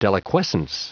Prononciation du mot deliquescence en anglais (fichier audio)